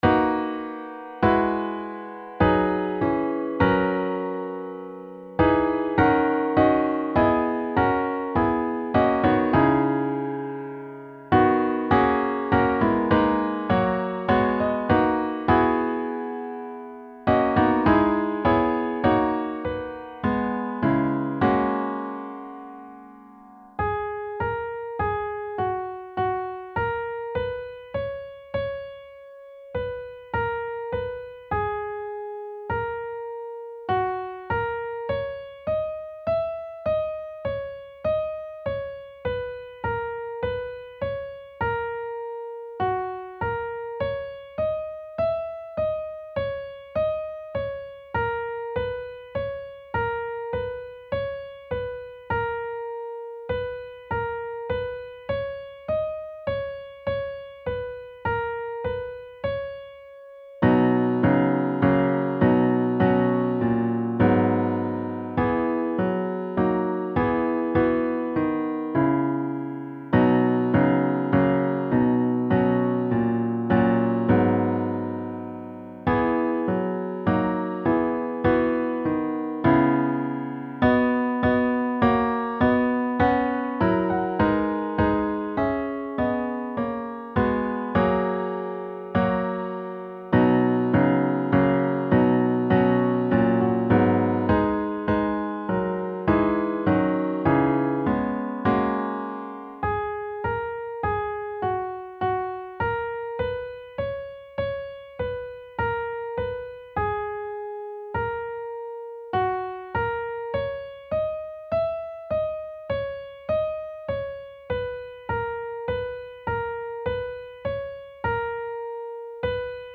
set for SATB a cappella
Choral
Voicing : SATB Accompaniment : a cappella